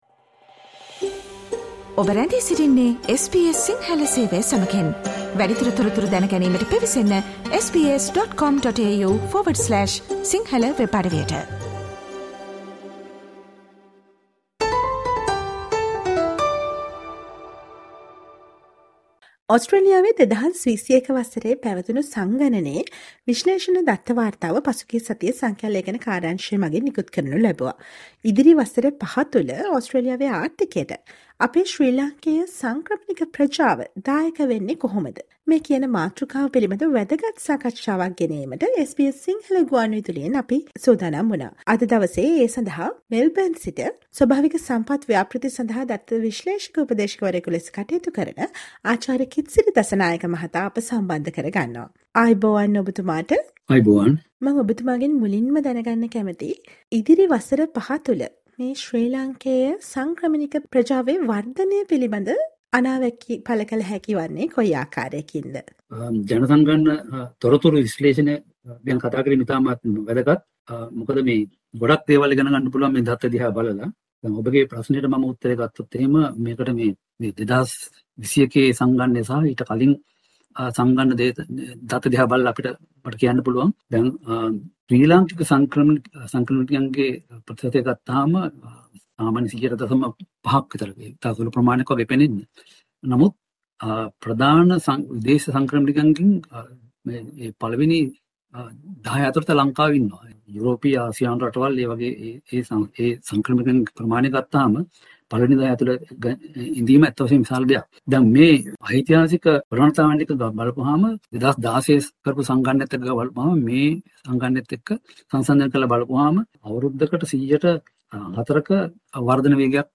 සාකච්ඡාව